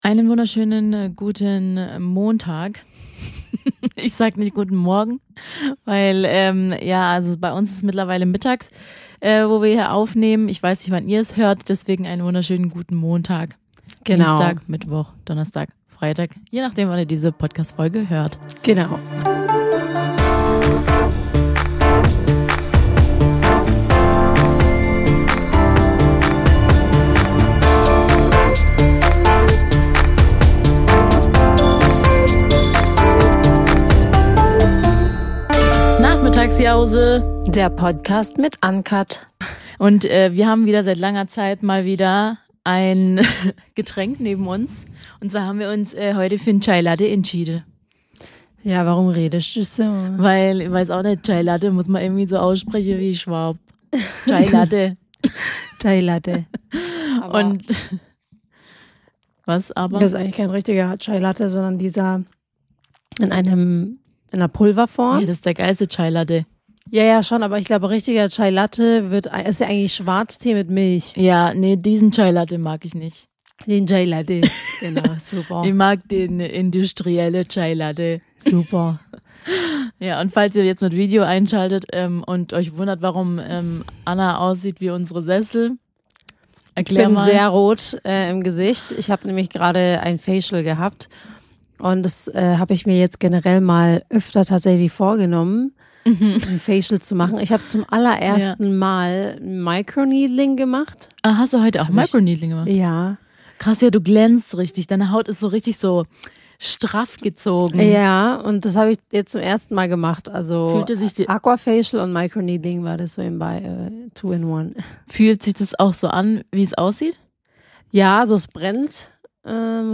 Wir sind zwei Schwestern, die sich gerne mal bei einem Kaffee und Kuchen unterhalten und über jeden Klatsch und Tratsch reden.